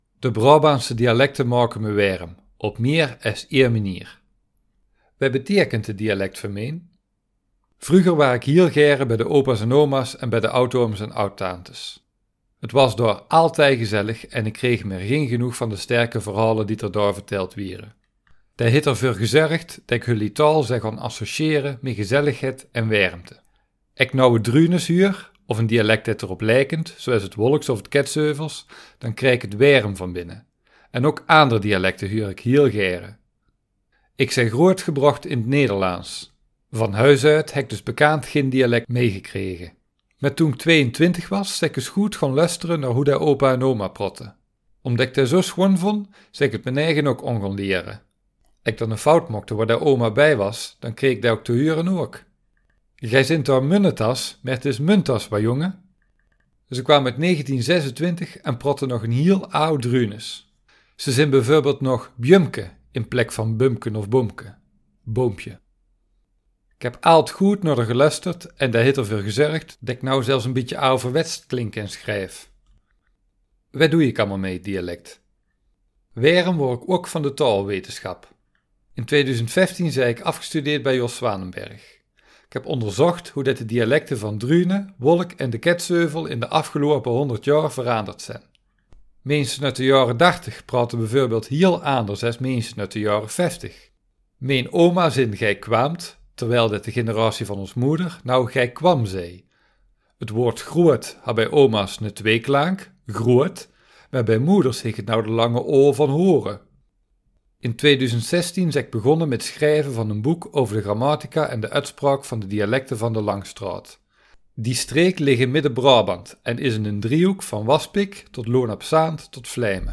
Geluidsopname tekst